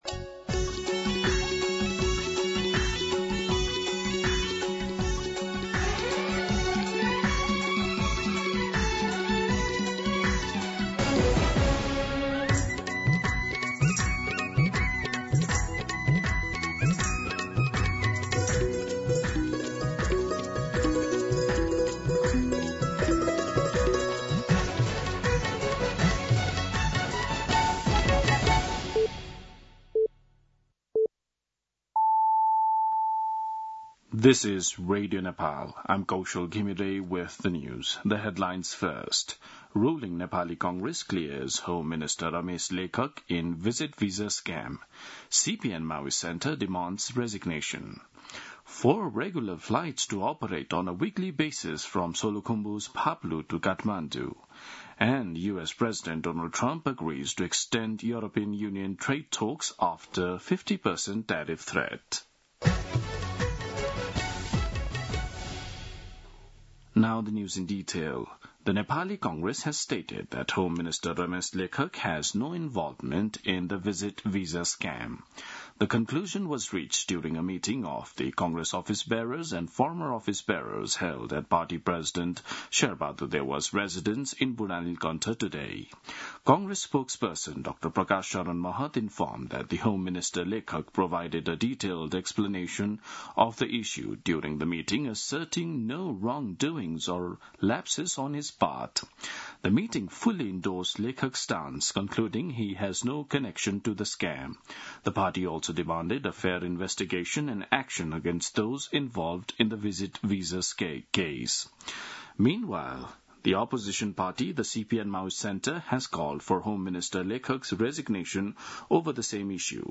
दिउँसो २ बजेको अङ्ग्रेजी समाचार : १२ जेठ , २०८२
2pm-English-News-12.mp3